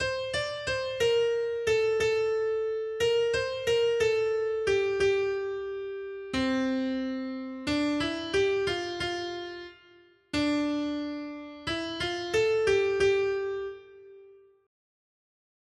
Noty Štítky, zpěvníky ol296.pdf responsoriální žalm Žaltář (Olejník) 296 Skrýt akordy R: Radostně půjdeme do domu Pánova. 1.